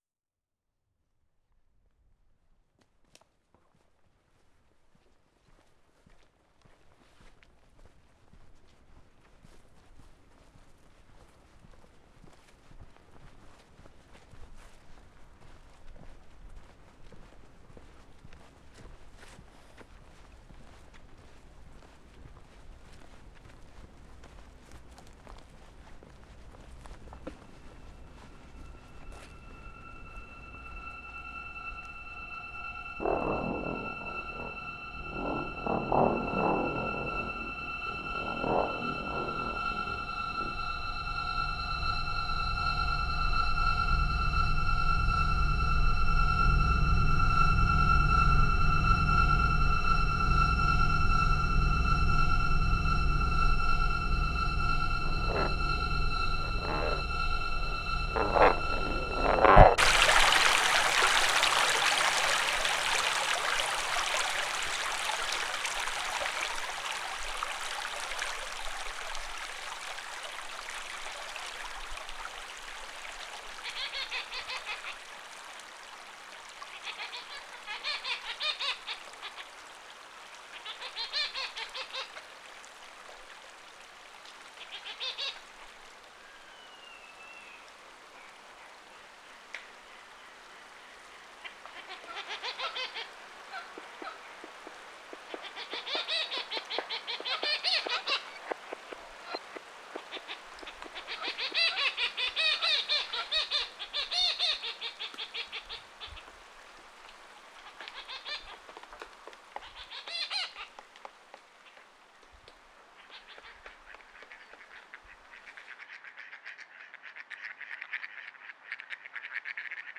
Keywords: integration of media, computer-generated sound, acoustic sound, sonic interaction, serial techniques, granular synthesis, contemporary concert music, electroacoustic music, mixed-media music, serial music, microsound